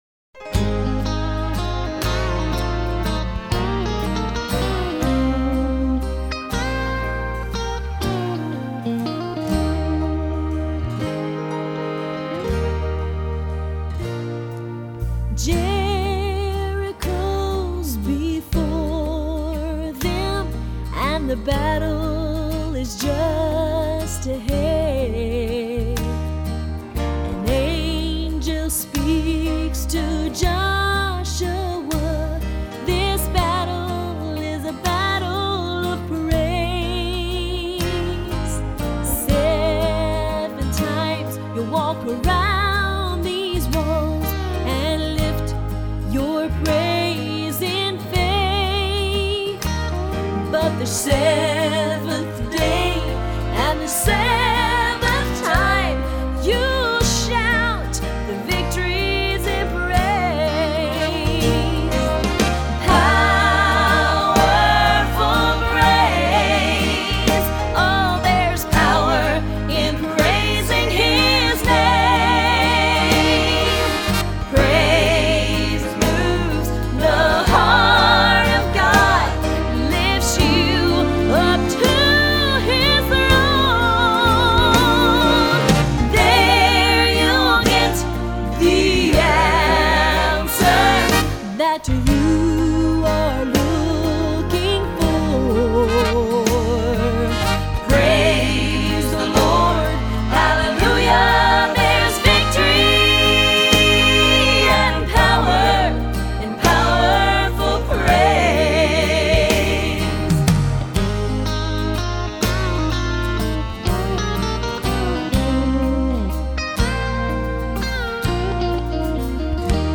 As recorded by multiple award winning soprano
gospel